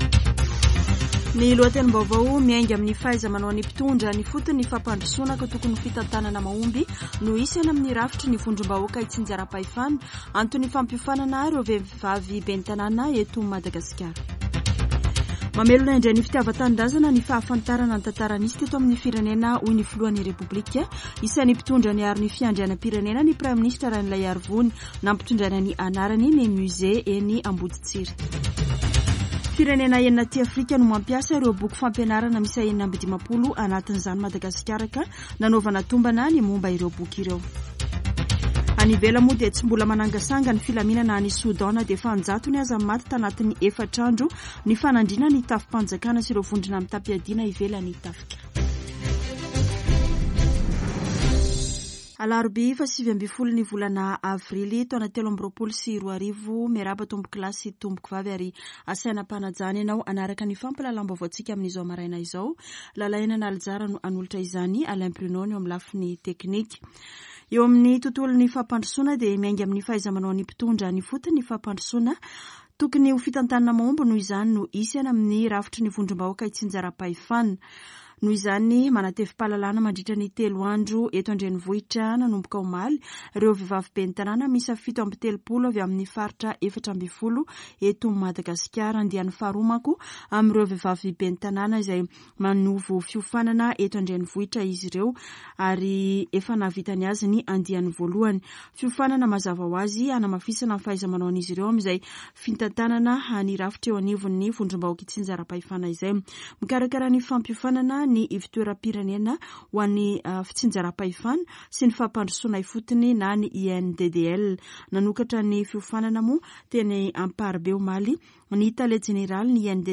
[Vaovao maraina] Alarobia 19 avrily 2023